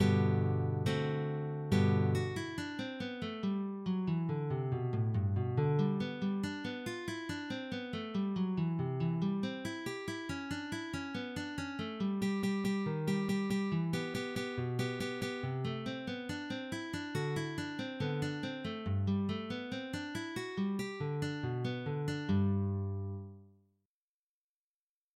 PRELUDIO